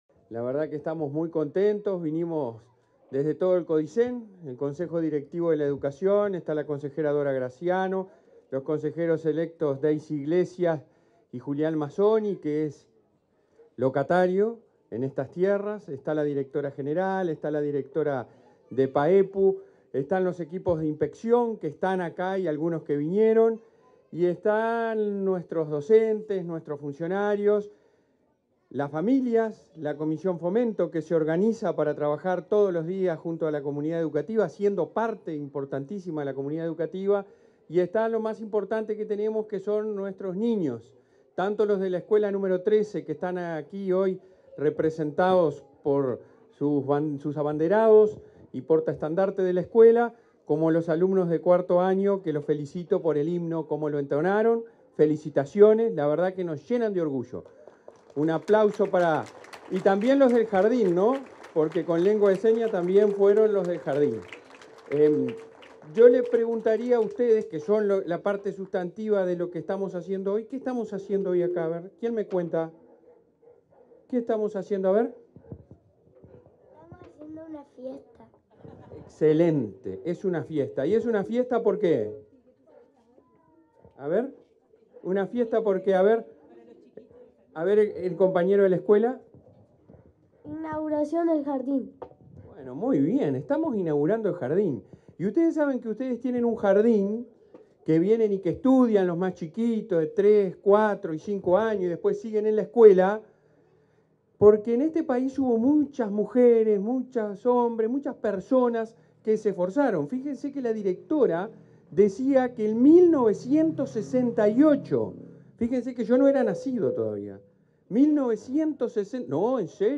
Palabras del presidente de ANEP, Robert Silva